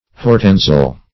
Search Result for " hortensial" : The Collaborative International Dictionary of English v.0.48: Hortensial \Hor*ten"sial\, a. [L. hortensius, hortensis, fr. hortus garden; akin to E. yard an inclosure.] Fit for a garden.